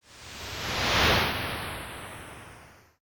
whoosh04
effect electronic fm soundeffect synth whoosh sound effect free sound royalty free Sound Effects